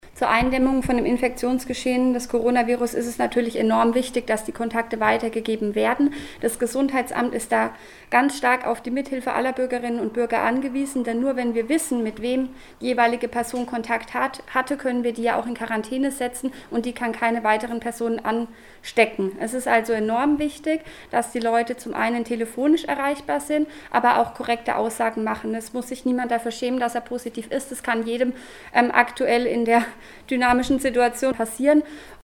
Besuch im Gesundheitsamt Schweinfurt- Alle Interviews und Videos zum Nachhören - PRIMATON